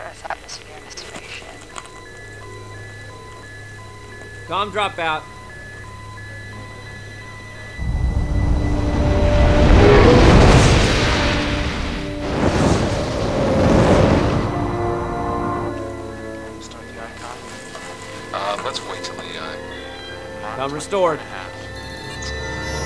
As far as I can tell, he appears in only one scene and his lines are comprised of a grand total of four, count 'em, four words.
Fred plays the role of a flight engineer monitoring communications with a space shuttle, appearing in the third scene of the movie ("Endeavour Landing"). The Endeavour space shuttle is coming in for a landing, and tensions rise as communication with the shuttle is momentarily disrupted.
The fact that his voice in this movie sounds nothing like his voice in other appearances didn't help me notice him either.